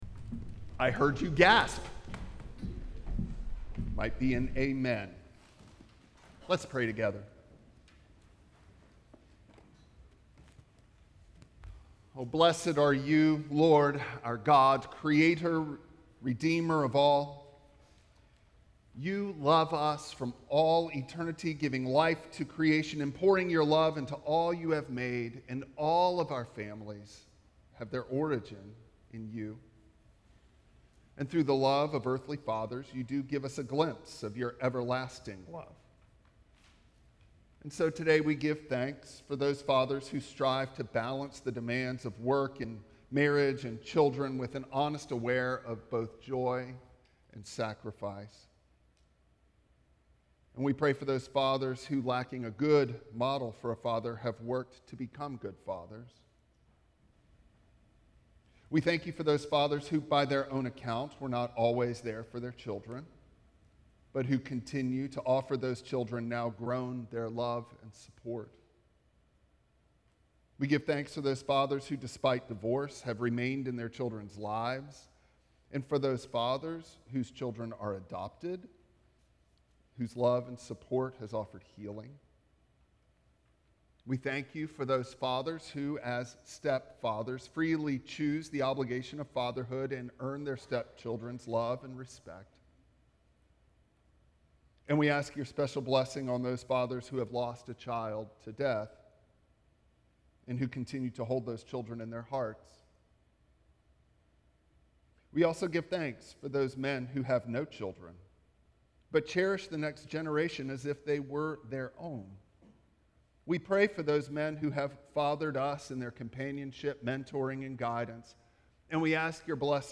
Matthew 7:15-20 Service Type: Traditional Service Bible Text